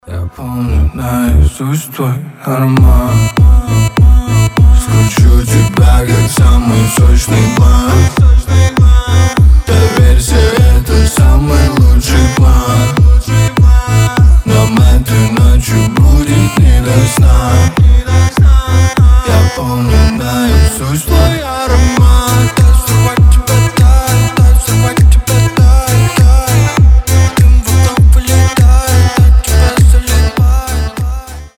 басы